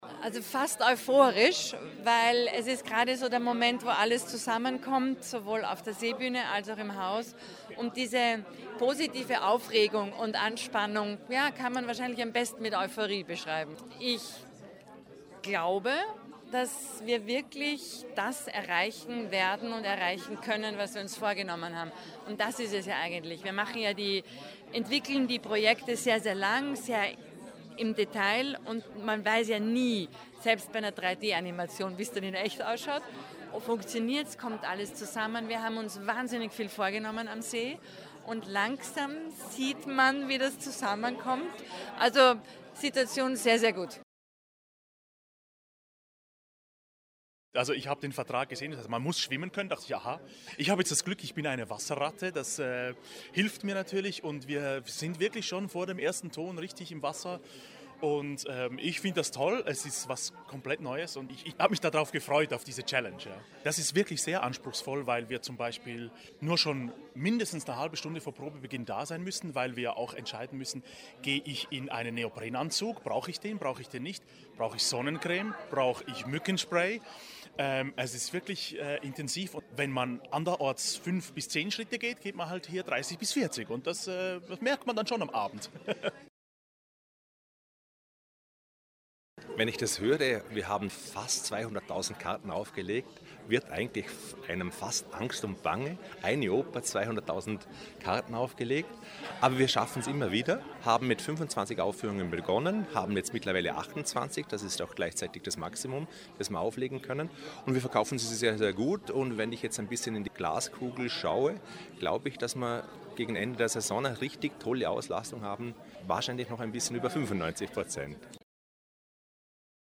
o-ton_bregenzer-festspiele_pressetag-i2024_feature.mp3